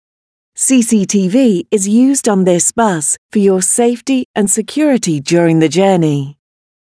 cctvoperation.mp3